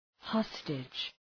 Προφορά
{‘hɒstıdʒ}